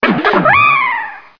P3D-Legacy / P3D / Content / Sounds / Cries / 554.wav